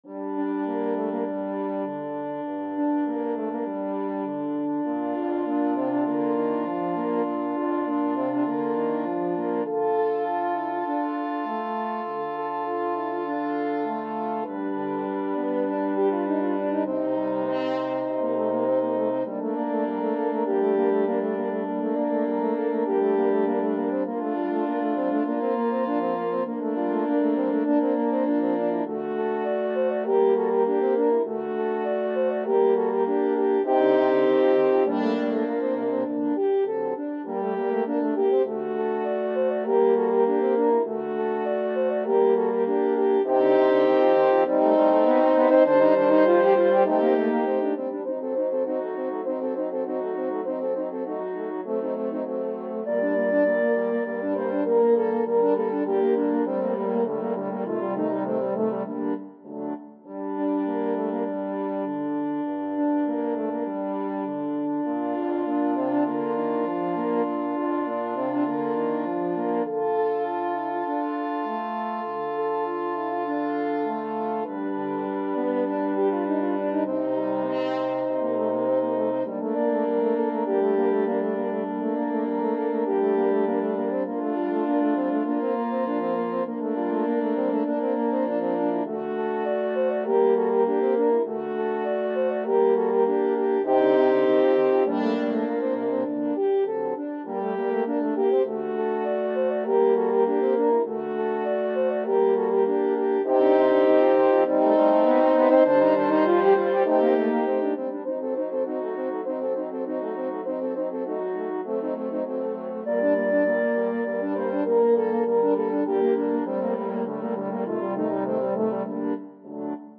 Free Sheet music for French Horn Quartet
4/4 (View more 4/4 Music)
G minor (Sounding Pitch) D minor (French Horn in F) (View more G minor Music for French Horn Quartet )
Allegro Moderato (View more music marked Allegro)
Classical (View more Classical French Horn Quartet Music)